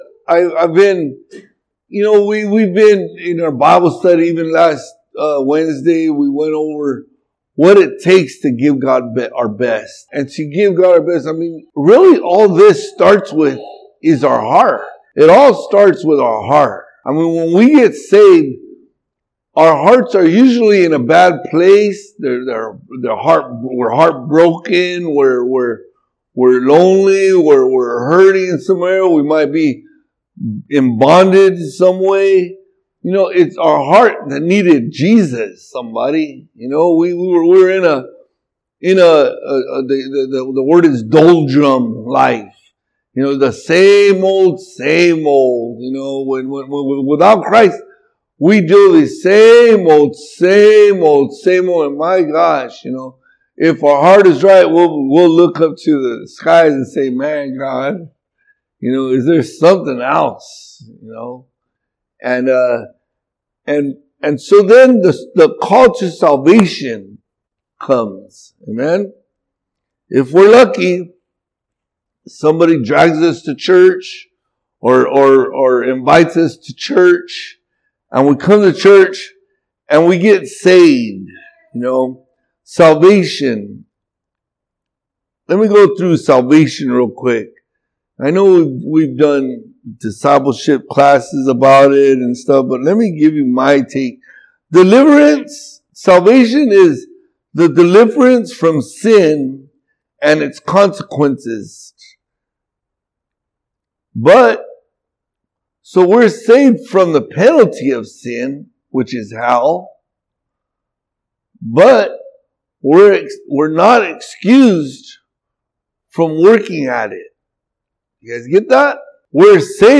All Sermons Born Again August 10